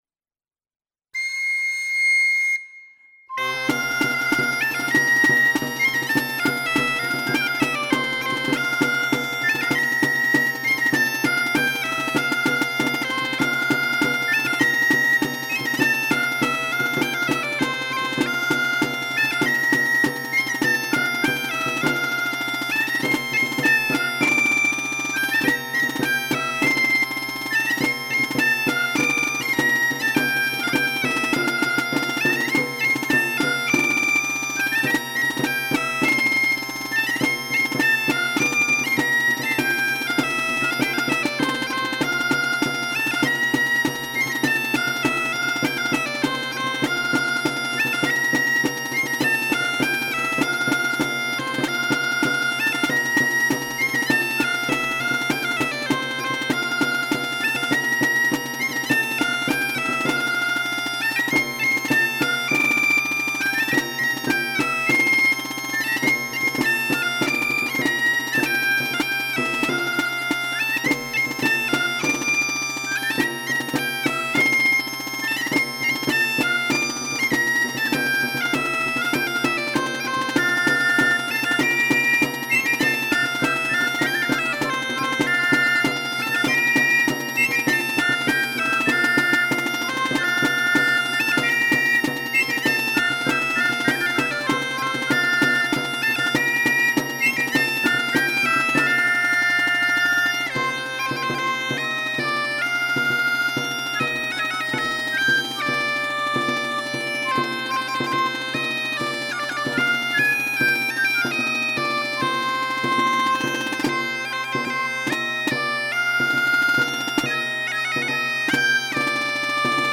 Oiartzungo Lezoti estudioan grabatuta.